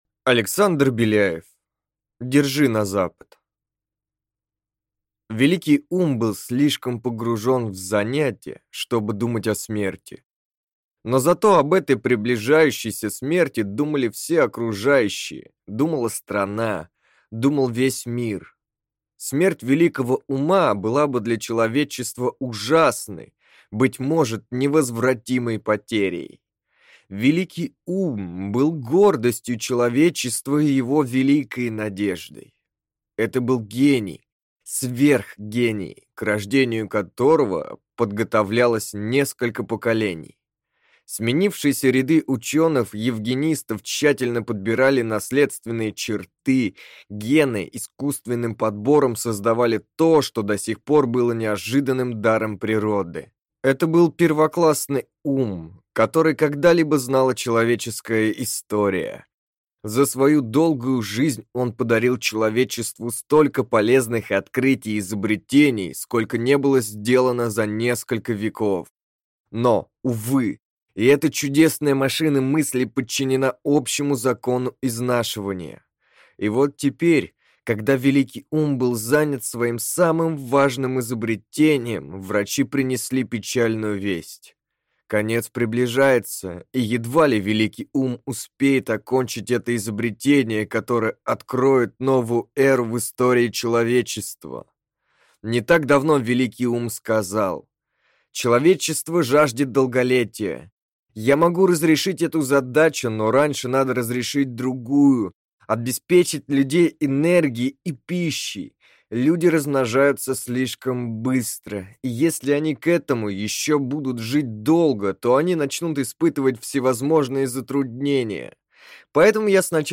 Аудиокнига Держи на запад!